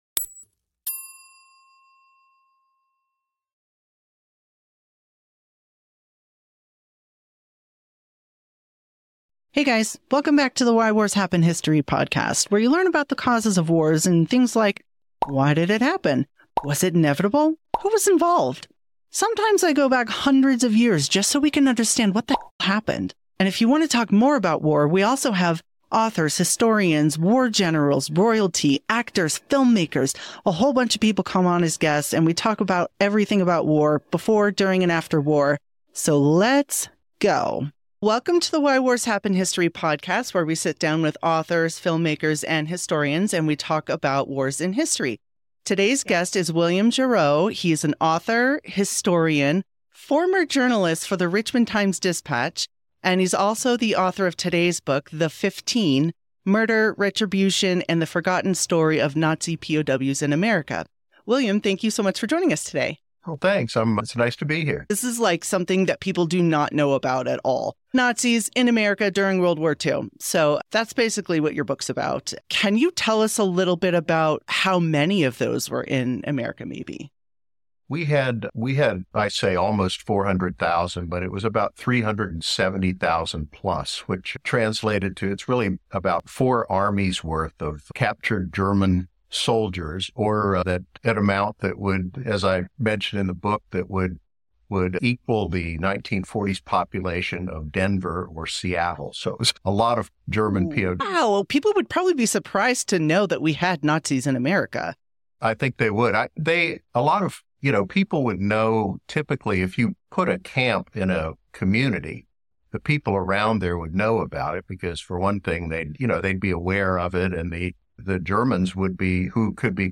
Why Wars Happened Interview History Podcast